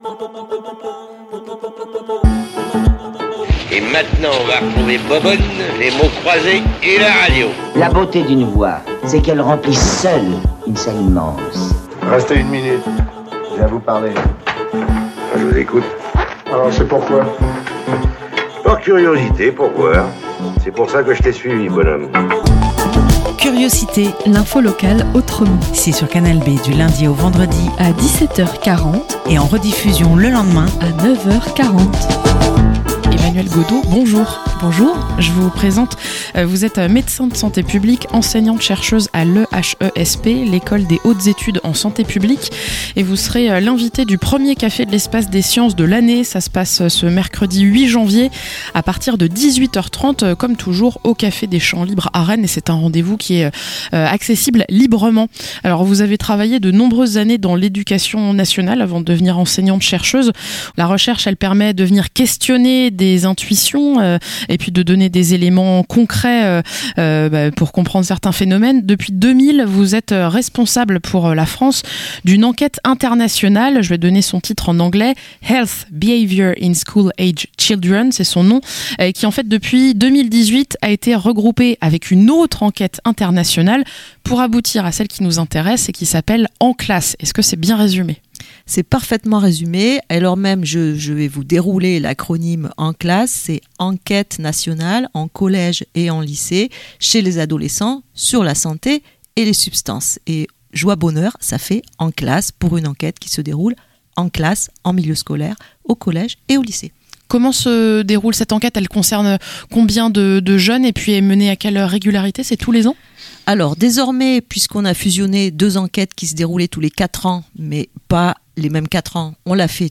- Interview